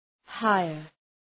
Προφορά
{‘haıər}